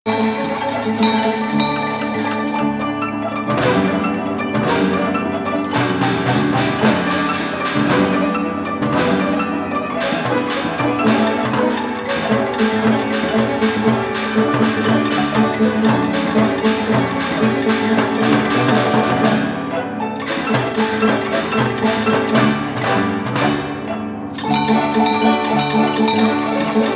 sur piano bastringue
danse : java
(musique mécanique)